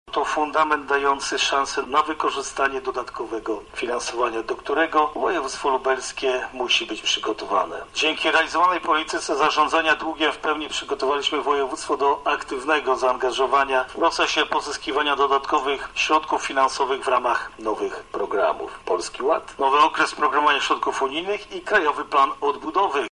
To budżet bardzo zrównoważony i ambitny – mówi marszałek województwa lubelskiego Jarosław Stawiarski: